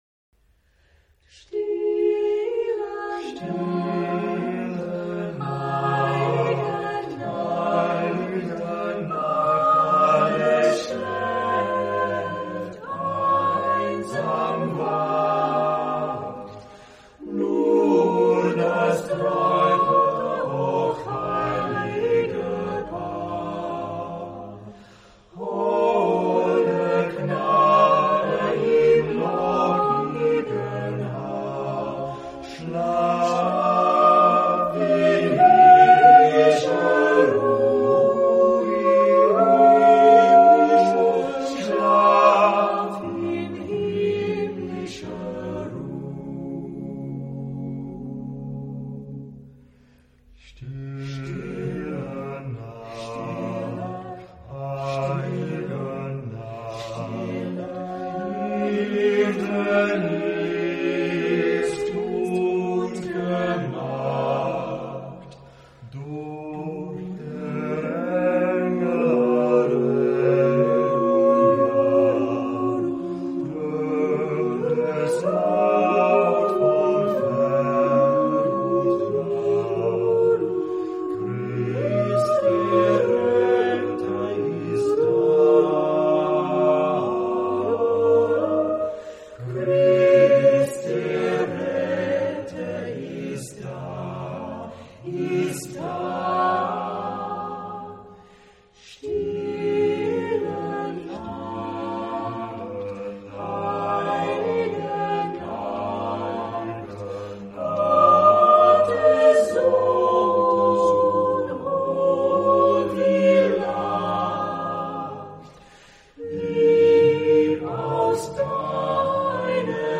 Carácter de la pieza : expresivo ; andante
Tipo de formación coral: SATB  (4 voces Coro mixto )
Tonalidad : re bemol (centro tonal)